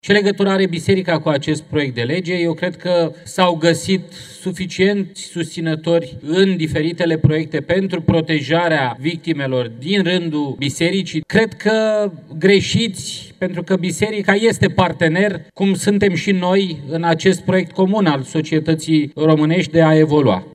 Reclamat anul trecut, la Parchetul General pentru violențe și amenințări, după ce a i-a spus Dianei Șoșoacă, fosta sa colegă, că o agresează sexual, George Simion a venit la tribuna Parlamentului pentru a lua apărarea Bisericii.